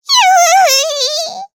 Taily-Vox_Damage_jp_03.wav